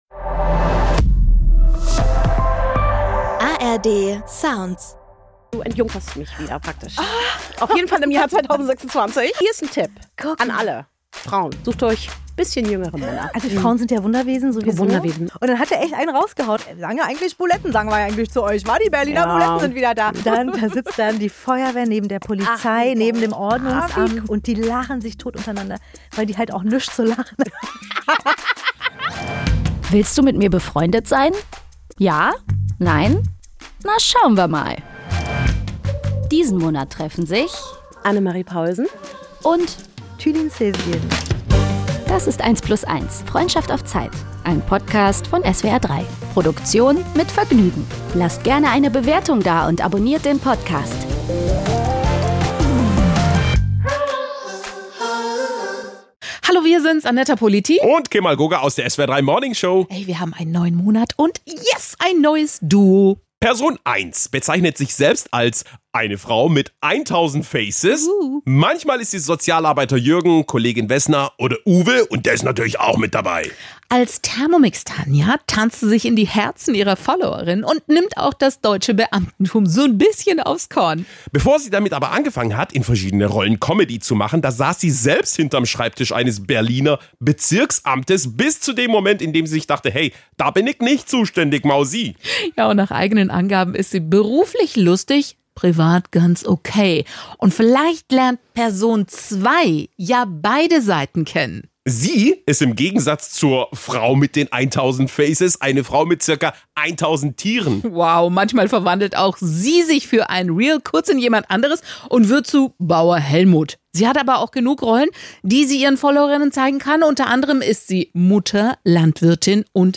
Wie sie sich im SWR3-Studio zurechtfinden?